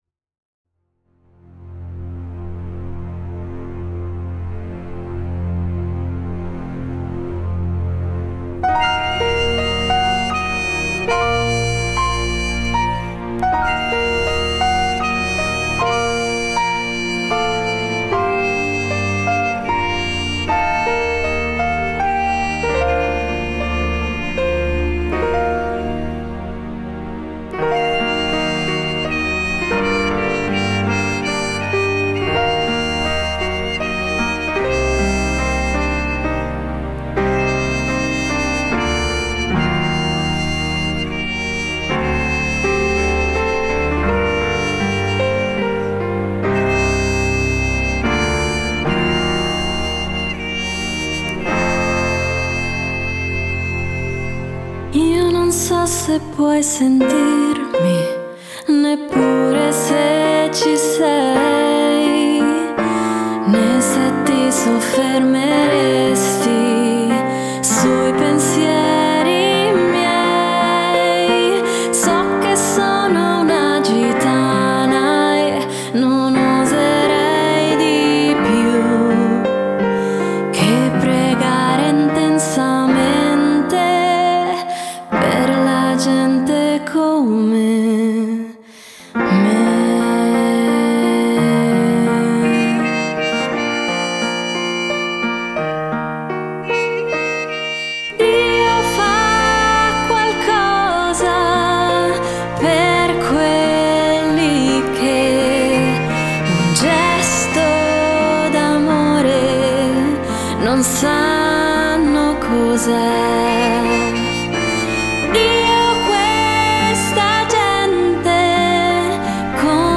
TROMBA
PIANOFORTE E VOCE
VOCE
SYNTH